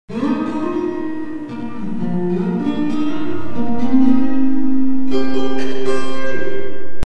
Reverb with 3 second reverb time and .5 decay
Reverb 3 sec 0.5 decay
clip03_7s_del3_dec5.mp3